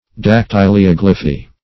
dactylioglyphy \dac*tyl`i*og"ly*phy\, n. The art or process of gem engraving.
dactylioglyphy.mp3